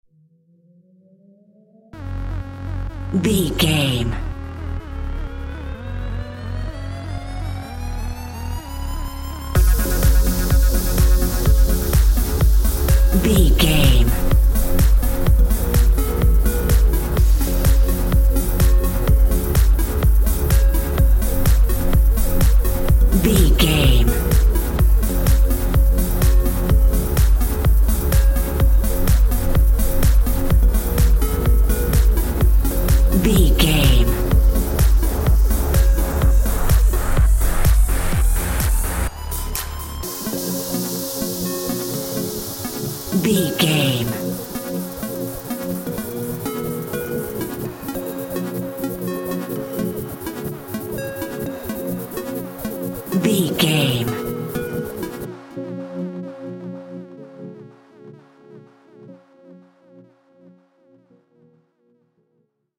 Aeolian/Minor
F#
groovy
dreamy
smooth
futuristic
drum machine
synthesiser
house
electro dance
instrumentals
synth drums
synth leads
synth bass
upbeat